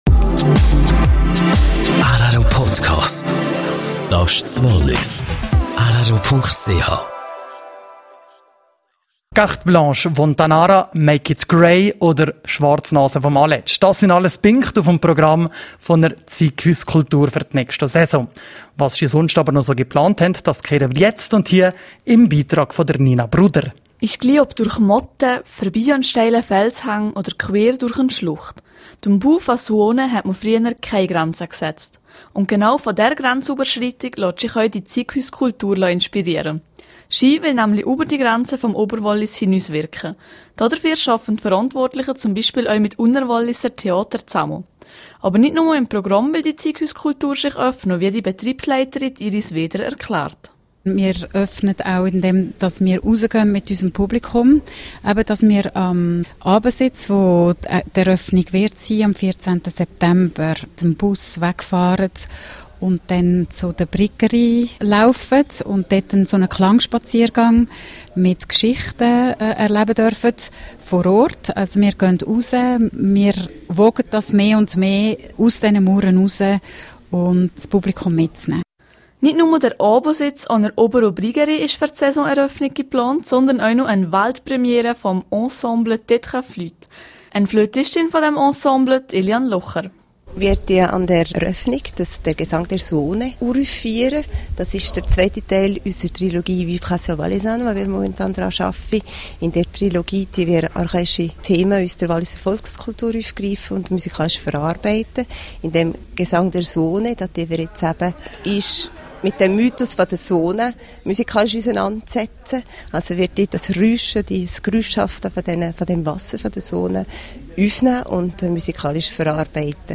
Zeughauskultur: Interview